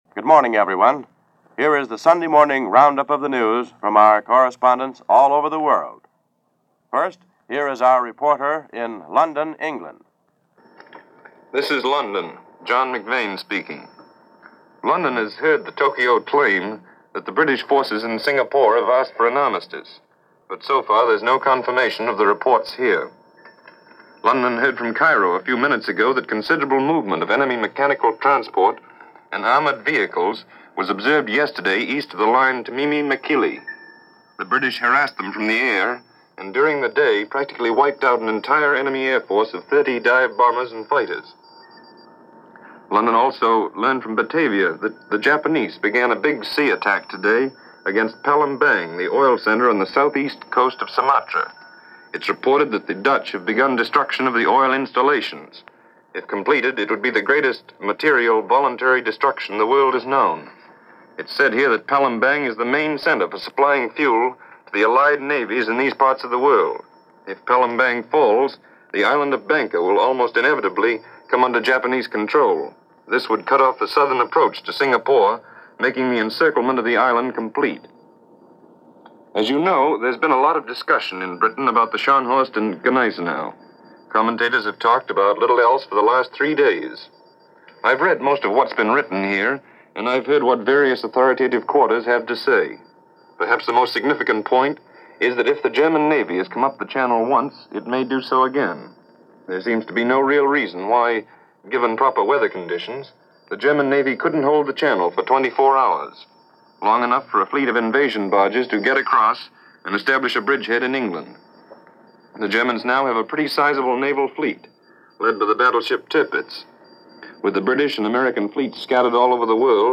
February 15, 1942 - Unexpected Audacity - World War 2 news
February 15, 1942 - Unexpected Audacity - reports from the Far East on the situation in Singapore - and on the Eastern Front.